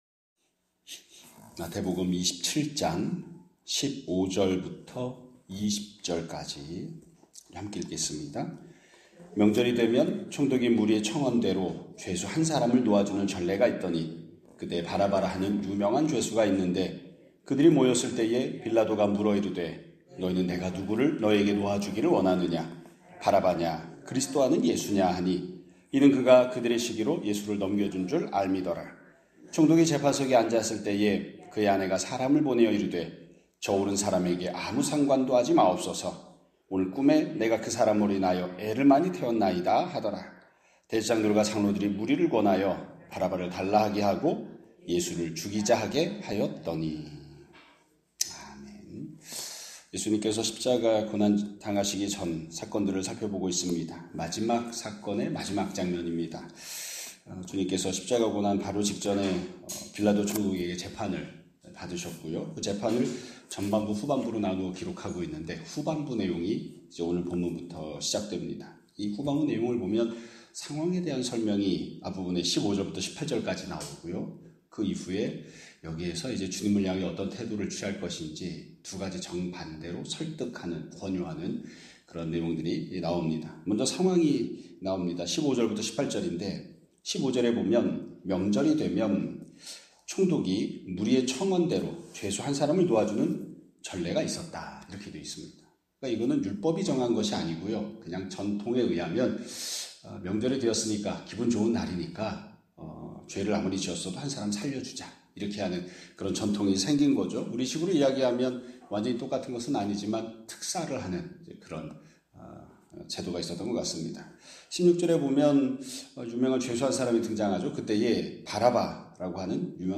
2026년 4월 16일 (목요일) <아침예배> 설교입니다.